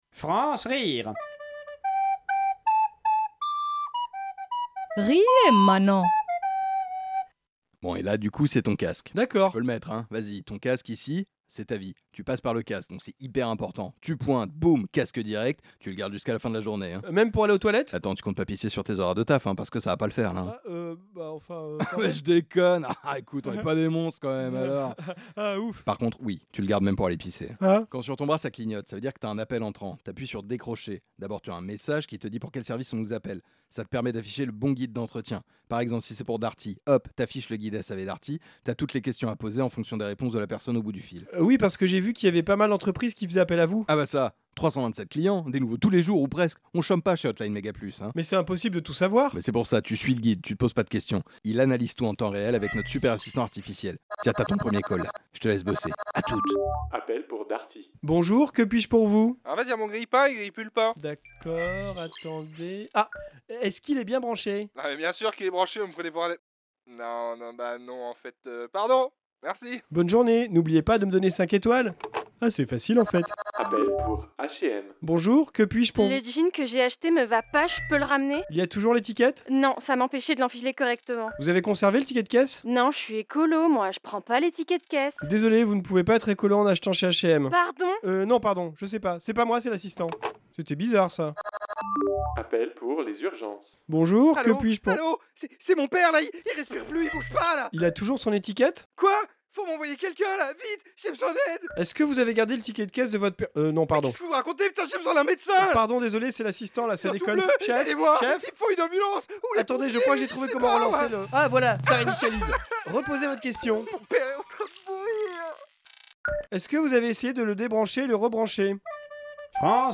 Création sonore
*Insérer ici les Quatre Saisons de Vivaldi*